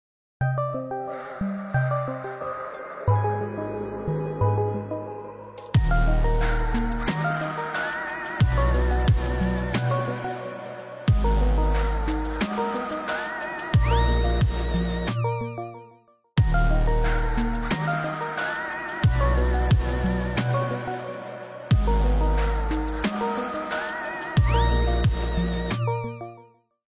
ремиксы , ужасы , mashup , страшные
без слов